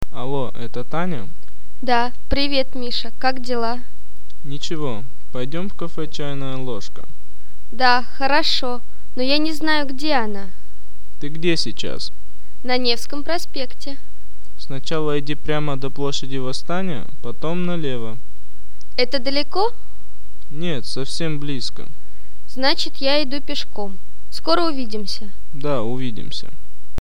Telefondialog
I telefon